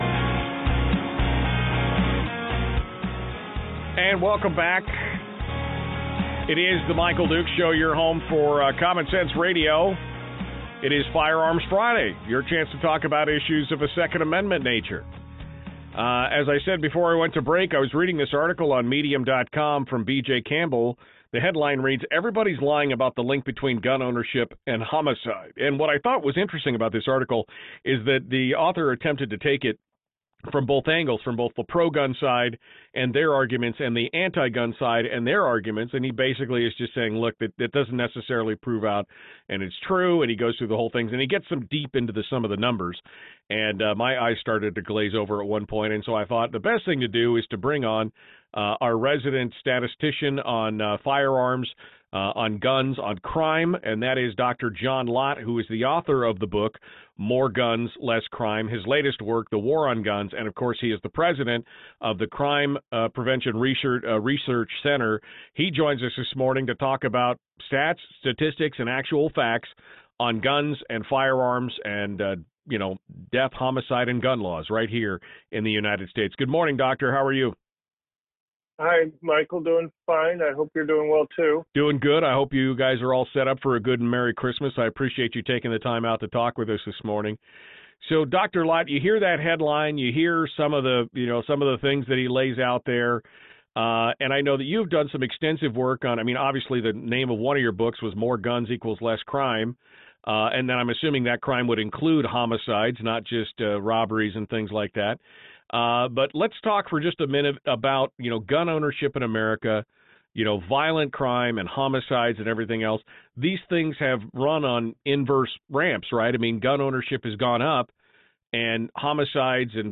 We talk with Dr. John R Lott Jr about the truth these issues.